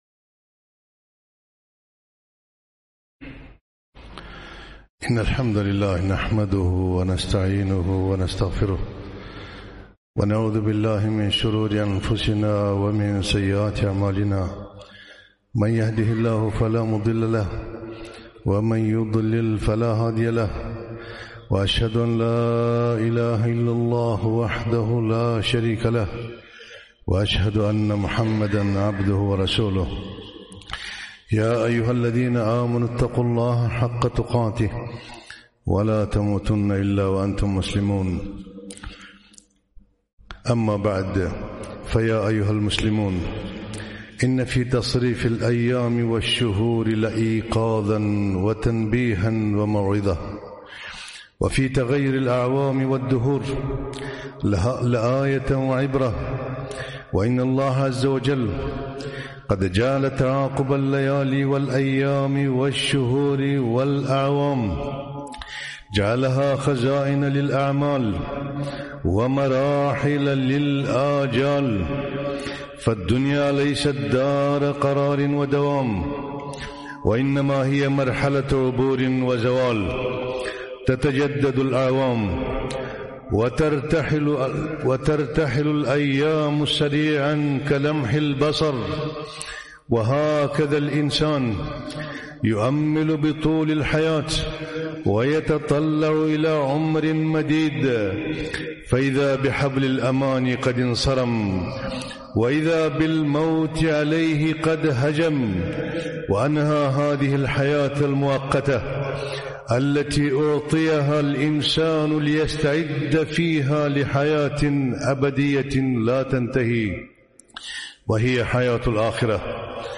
خطبة - شهر الله المحرم